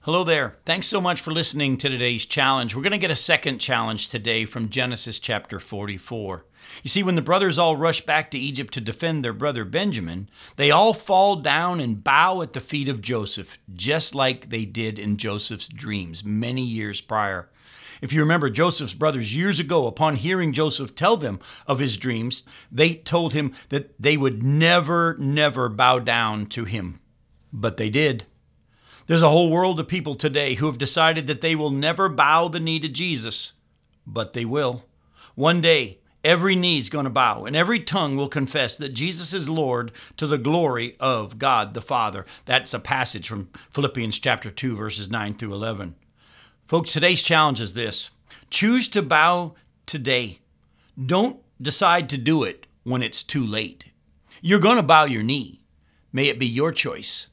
Challenge for Today Radio Program